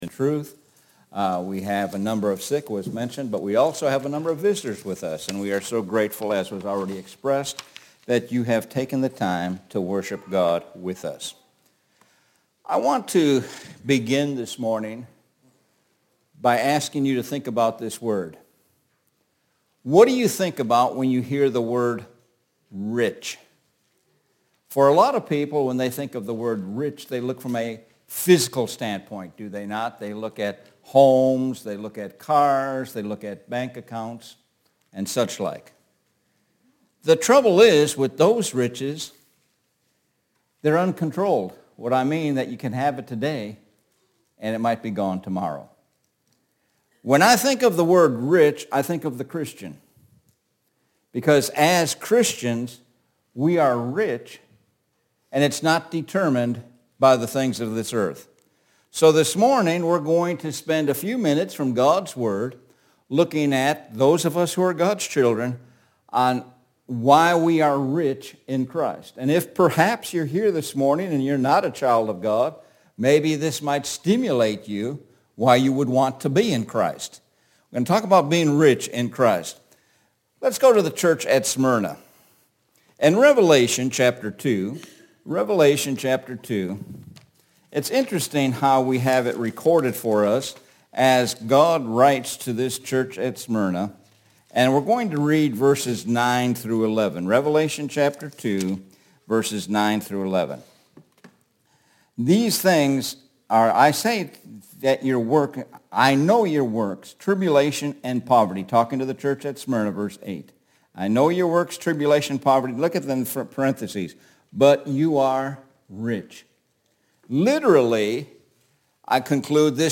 Sun AM Sermon – Rich in Christ